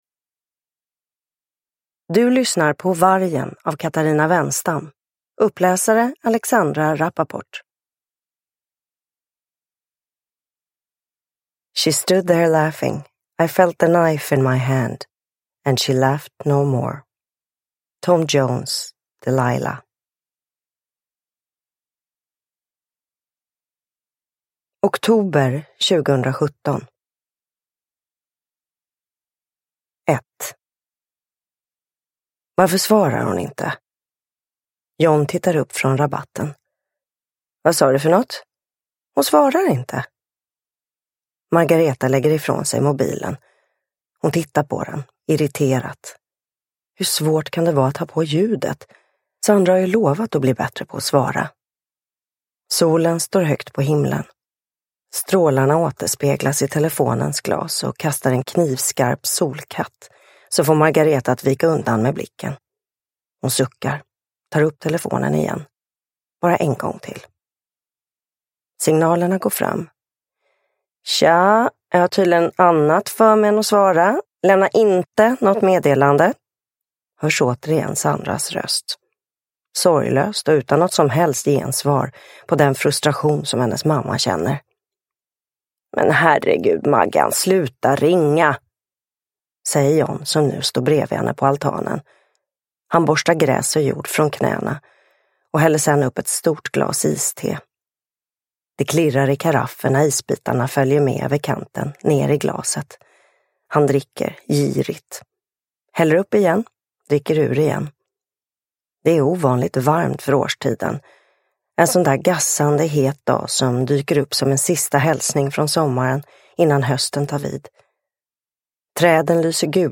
Uppläsare: Alexandra Rapaport
Ljudbok